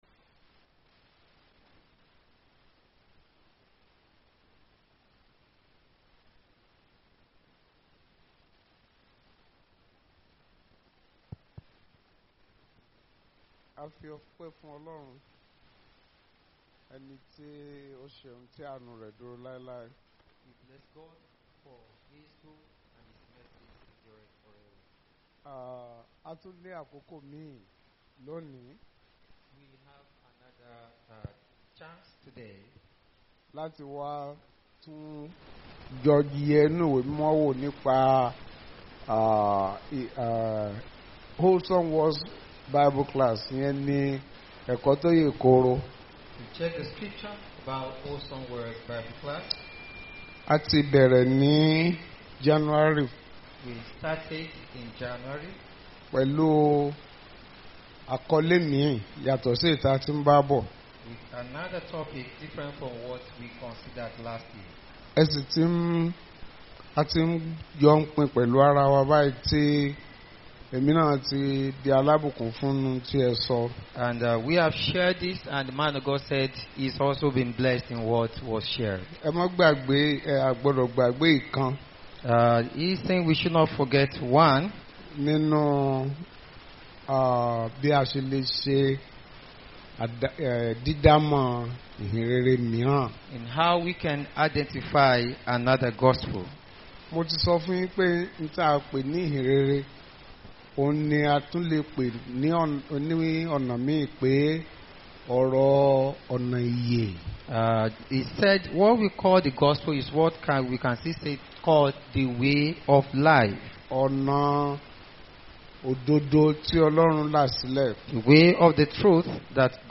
Bible Class Passage: 2 Corinthians 11:1-15